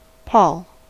Ääntäminen
IPA : /pɔːl/